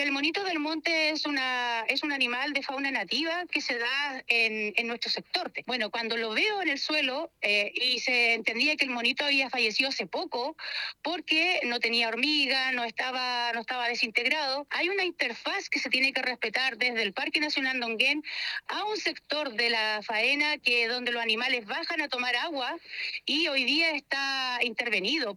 La concejala Ximena Bravo de Chiguayante manifestó que hace unos días se encontró un Monito del Monte muerto en el camino, animal en peligro de extinción, lo que enfureció a vecinos y que, según ella, es el resultado de la intervención de su hábitat natural.